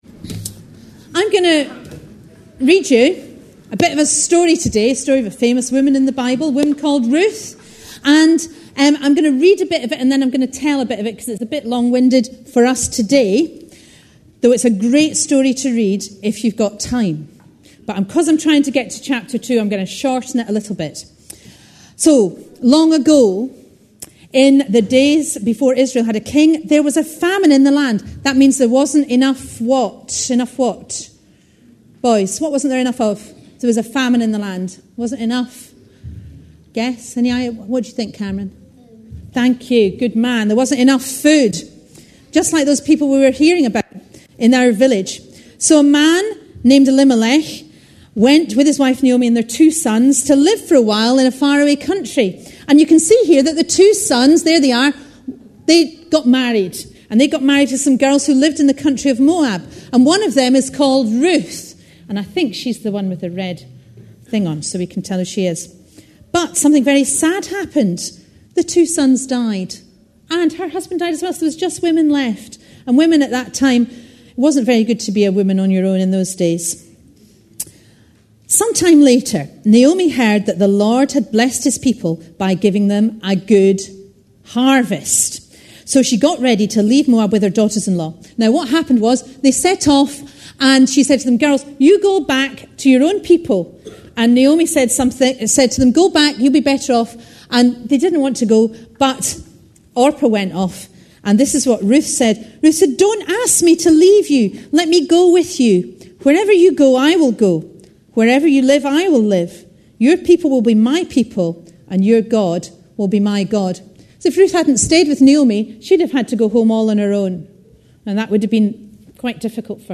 All-Age Service for Harvest
A sermon preached on 19th September, 2010.
Ruth Listen online Details This was a harvest-themed family service, with the offering for the Tahaddi Dental Project, Lebanon.